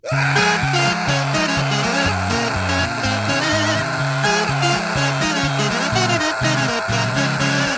freak-out.mp3